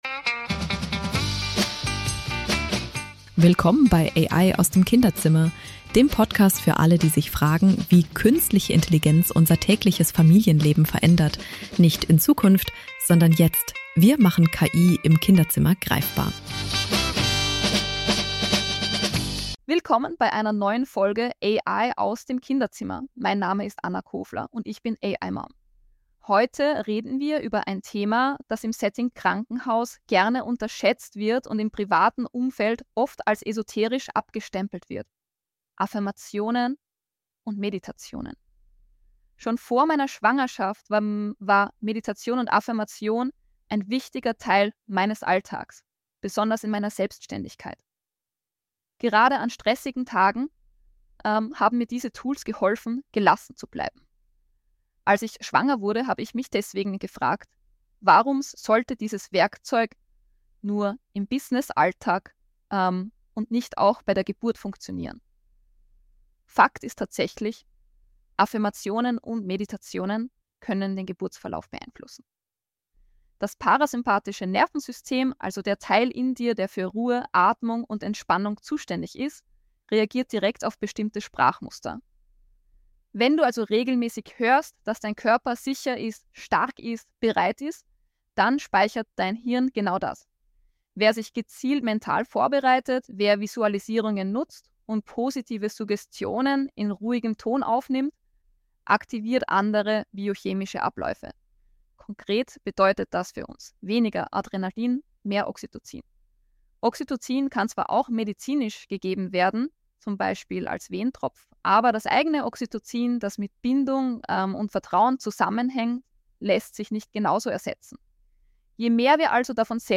Eine meiner besonderen Stärken ist die Transformation von bestehenden Texten in verschiedene Formate – etwa die Planung und Produktion einer Podcast-Episode auf Basis eines Blogbeitrags, inklusive KI-gestützter Audio-Ausspielung mit dem Stimmklon des Unternehmens.
KI-generierten Podcast von AI-Mom hören